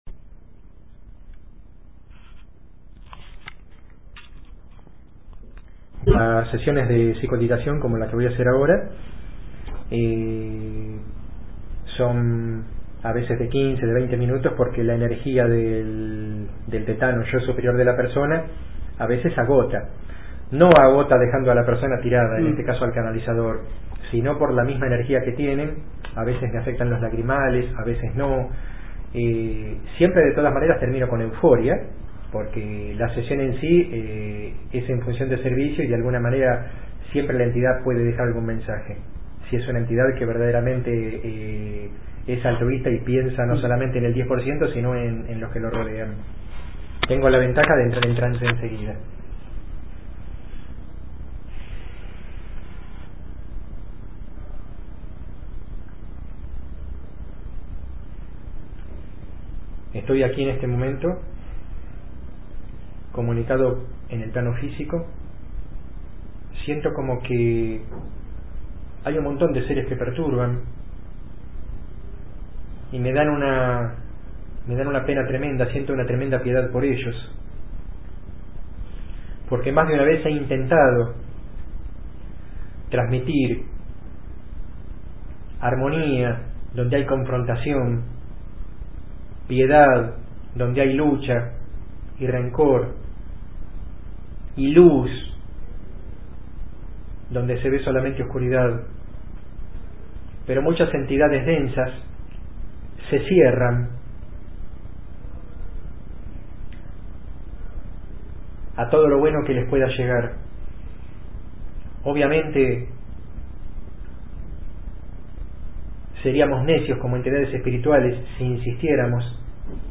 Psicoauditación del 13/7/07 Médium